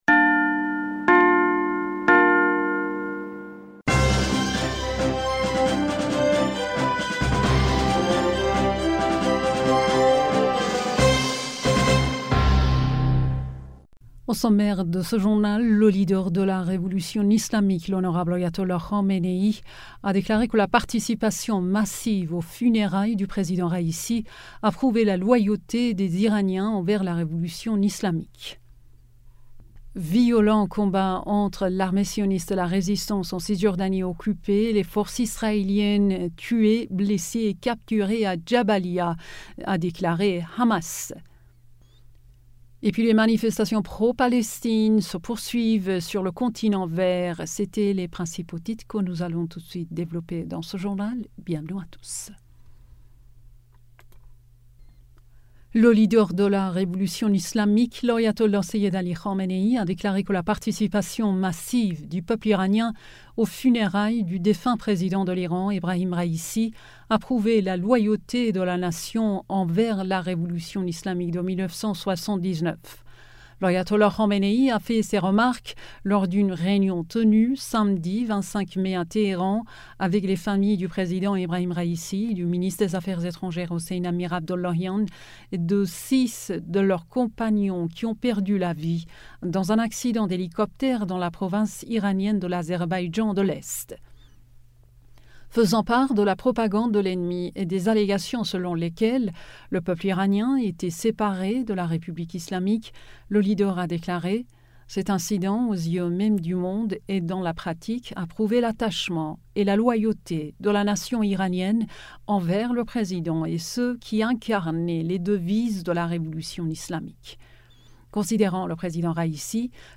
Bulletin d'information du 26 Mai